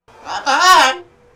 Reasons for recording a talking bird
Recording Birds